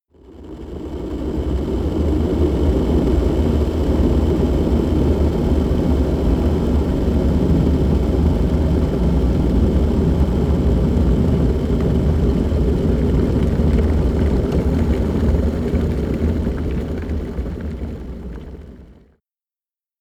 Unit 02 Kettle Boiling